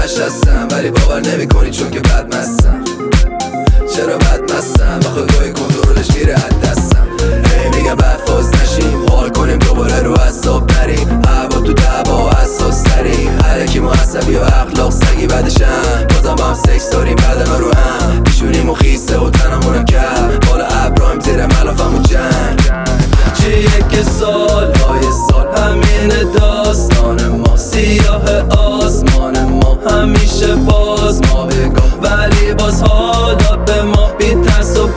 Hip-Hop Rap Dance House
Жанр: Хип-Хоп / Рэп / Танцевальные / Хаус